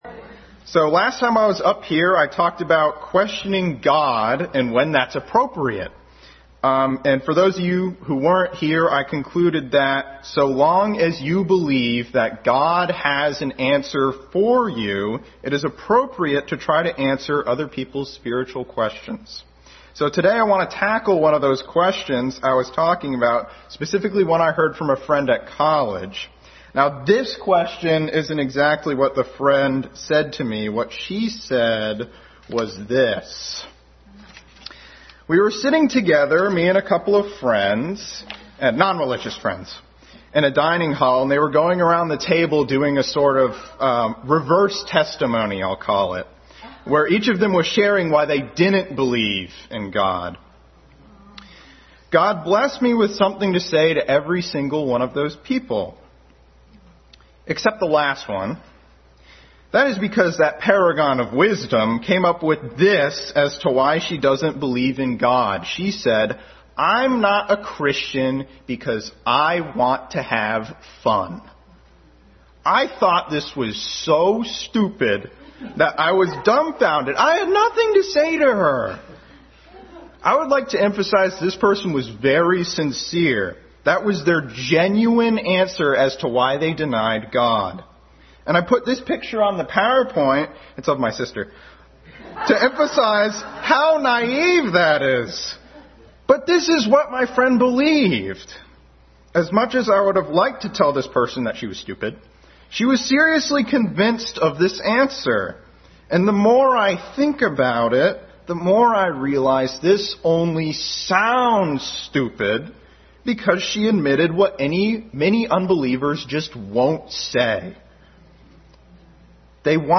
Family Bible Hour message.
Passage: Genesis 2:1-3, John 2:1-3, 6-10, Psalm 16:11, 37:4, 2 Samuel 6:21, Proverbs 15:15, Romans 5:3-4, 8:5-6 Service Type: Family Bible Hour Family Bible Hour message.